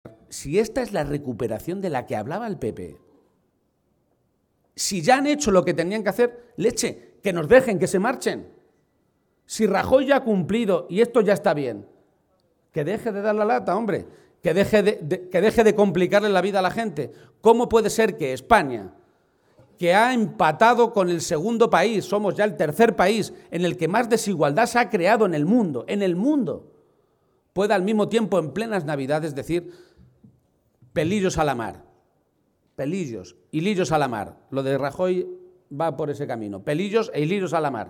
García-Page realizó estas declaraciones tras la tradicional comida navideña que celebraron los socialistas albaceteños y a la que asistieron cerca de 400 militantes y simpatizantes del conjunto de la provincia.
Audio García-Page comida PSOE Albacete-3